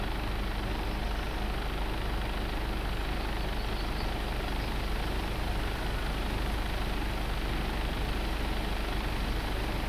Index of /server/sound/vehicles/lwcars/truck_2014actros
idle.wav